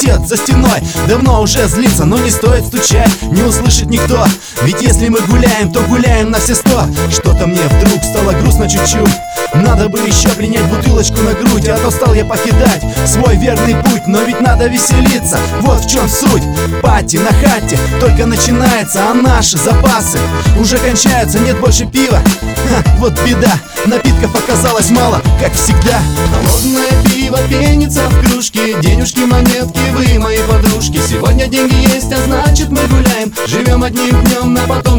Жанр: Рэп и хип-хоп / Русские
# Hip-Hop